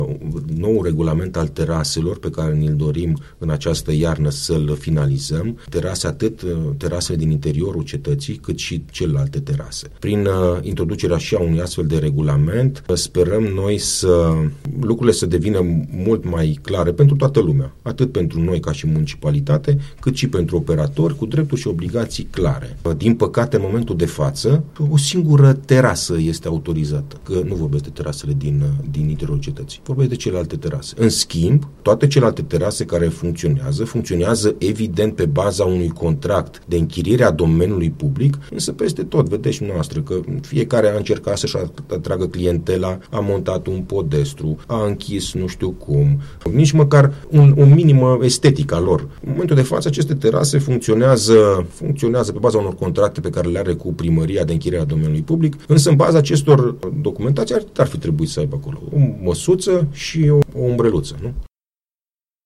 Este afirmația făcută la Unirea FM de viceprimarul municipiului Alba Iulia, Emil Popescu.